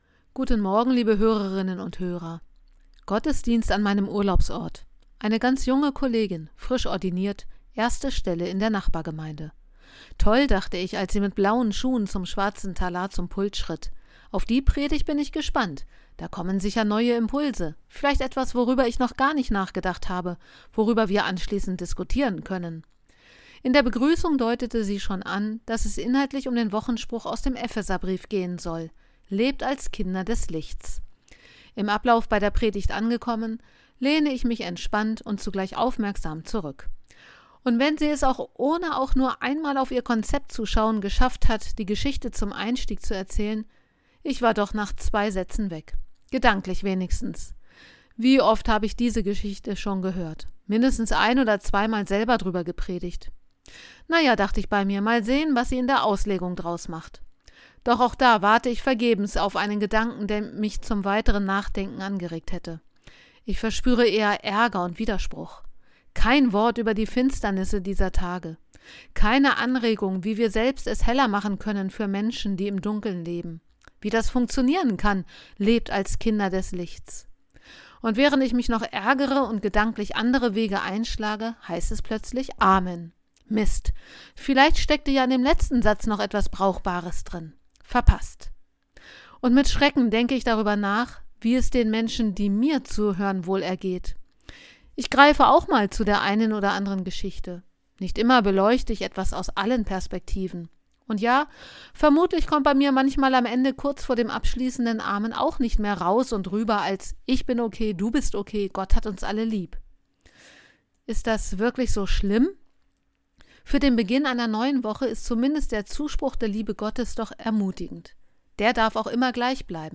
Radioandacht vom 29. Juli